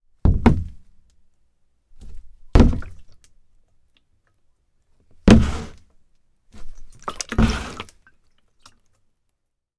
描述：塑料气体容器放在柜台上全重的砰砰嗖嗖和滑动干燥室.wav
Tag: 扑扇 晃动 滑动 向下 塑料 气体 计数器 容器 充满 干燥